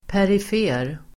Uttal: [pärif'e:r]